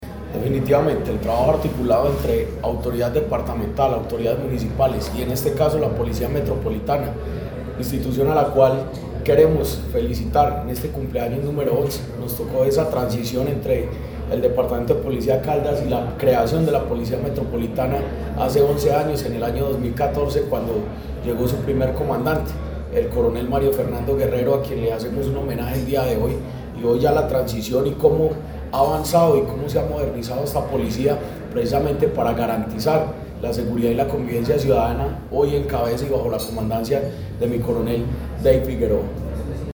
Jorge Andrés Gómez Escudero, secretario de Gobierno de Caldas